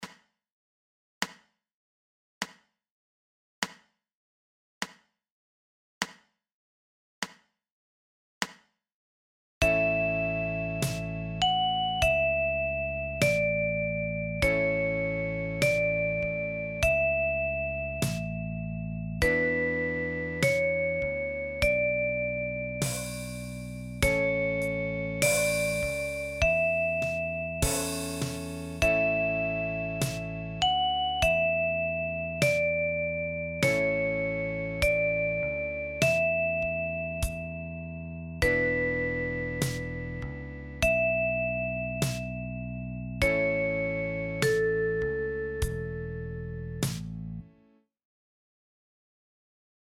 Kinderlieder aus aller Welt für die Mandoline + Sounds